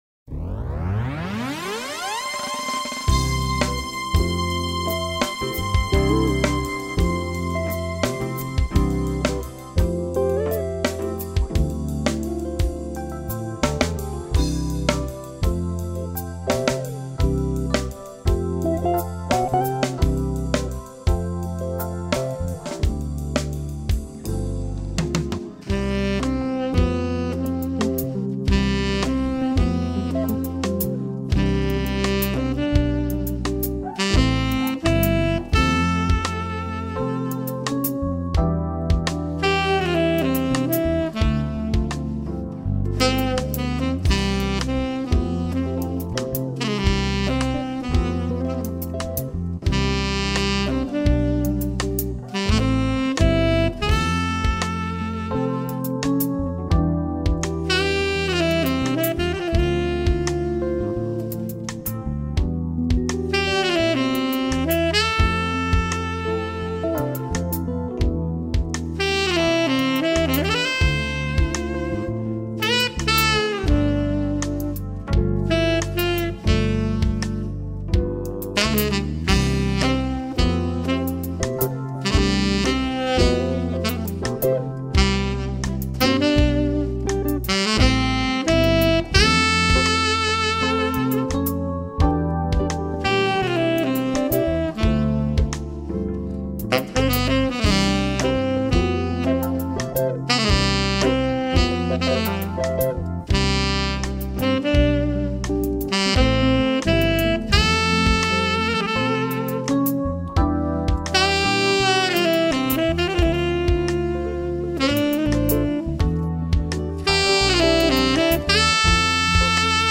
2025   04:18:00   Faixa:     Instrumental